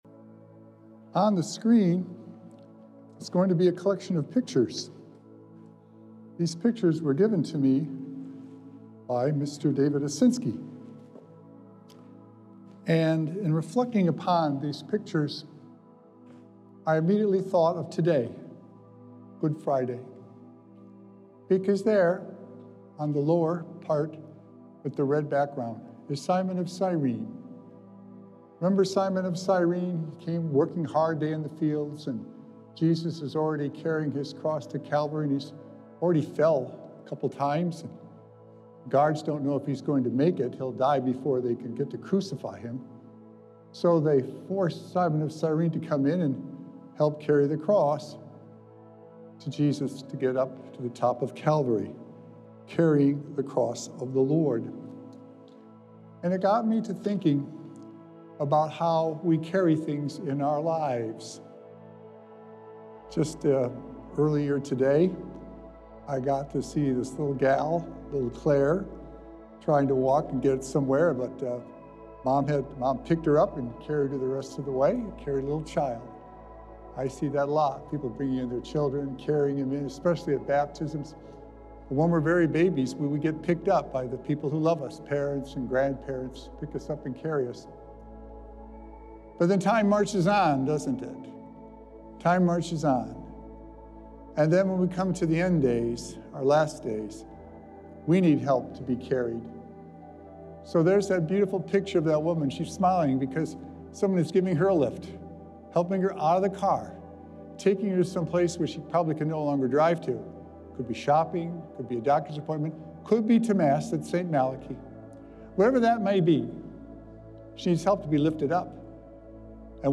Sacred Echoes - Weekly Homilies Revealed
We have the duty to show the love of Christ through our love and our actions. Recorded Live on Friday, April 18th, 2025 at St. Malachy Catholic Church.